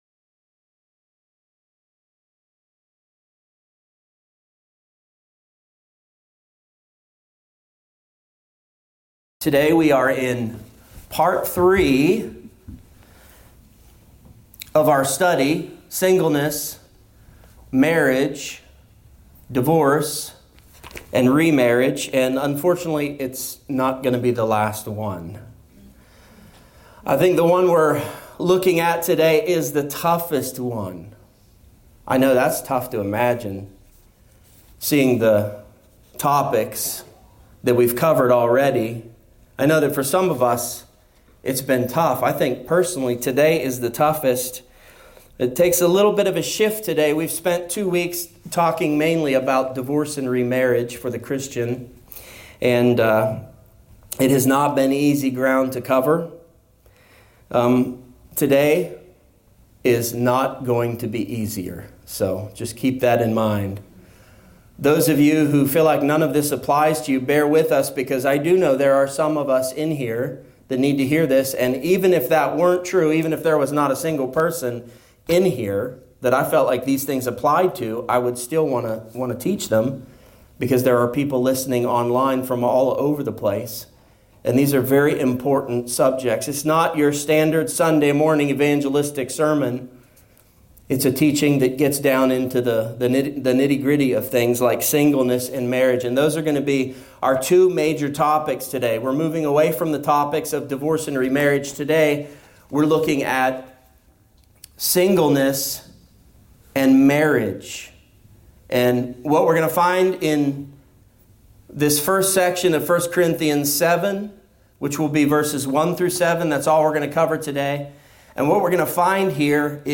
A topical teaching on the subjects of singleness, marriage, divorce, and remarriage.